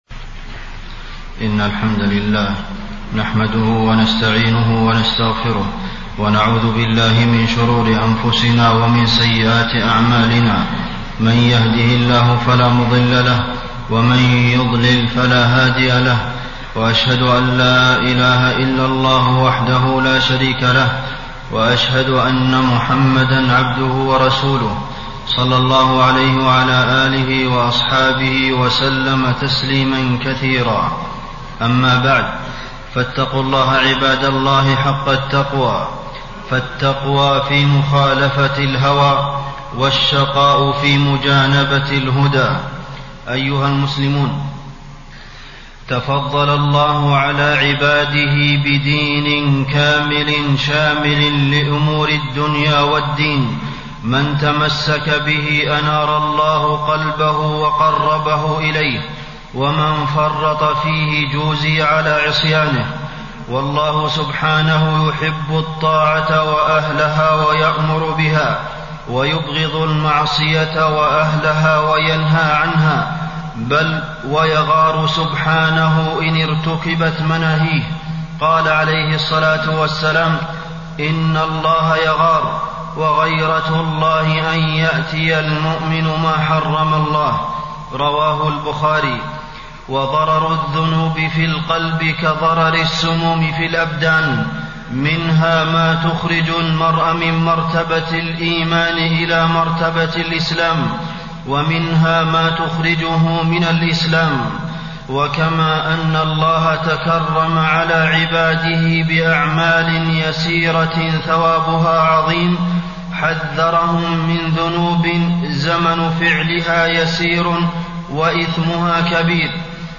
تاريخ النشر ٩ ربيع الثاني ١٤٣٣ هـ المكان: المسجد النبوي الشيخ: فضيلة الشيخ د. عبدالمحسن بن محمد القاسم فضيلة الشيخ د. عبدالمحسن بن محمد القاسم أعمال يسيرة وأجورها كبيرة